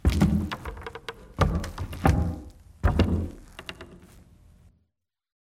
amb_fs_stumble_wood_06.mp3